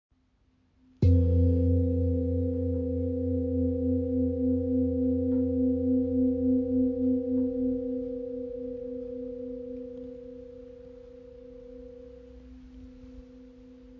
Klangschale ø 27.5 cm im Raven-Spirit WebShop • Raven Spirit
Klangbeispiel
Details dieser Schale: Durchmesser: 27.5 cm | Ton: ~F | Zubehör: 1 Klangschalenreiber (Holzklöppel mit Lederumwicklung)